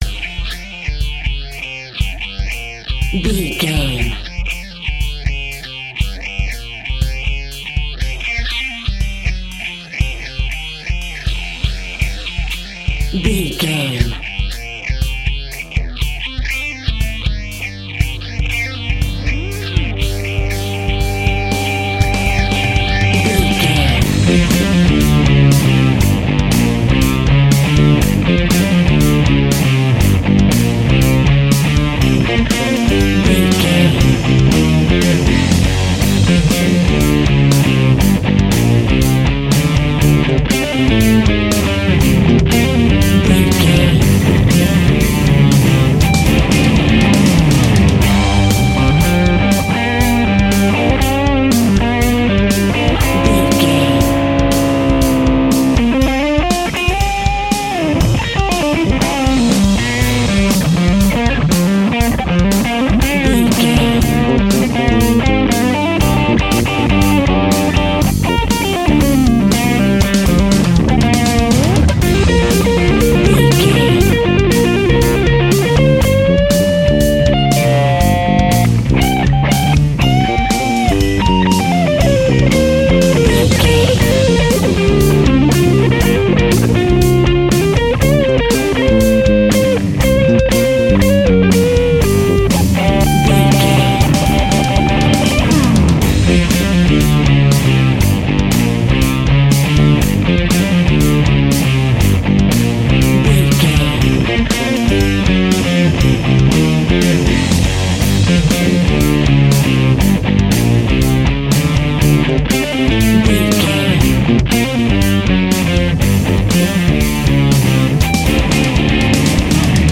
Epic / Action
Aeolian/Minor
hard rock
heavy metal
blues rock
distortion
rock guitars
Rock Bass
heavy drums
distorted guitars
hammond organ